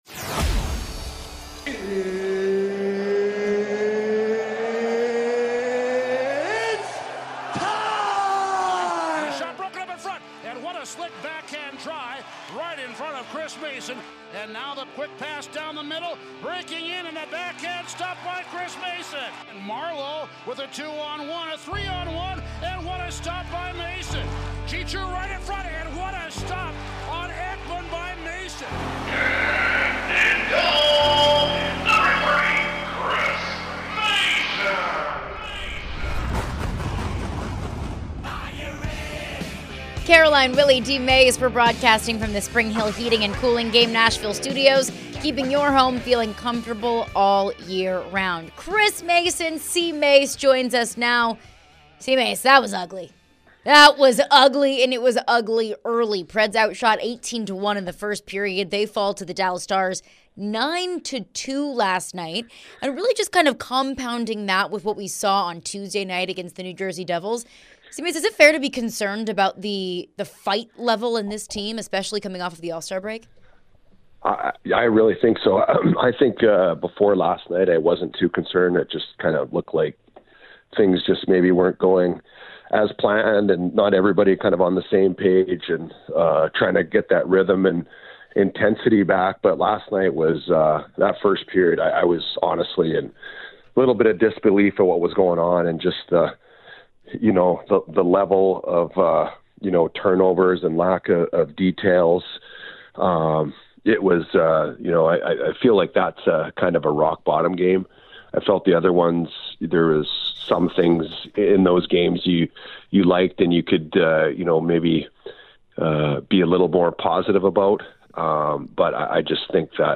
Headliner Embed Embed code See more options Share Facebook X Subscribe Chris Mason joined the show and discussed the recent slump of form from goaltender Juuse Saros.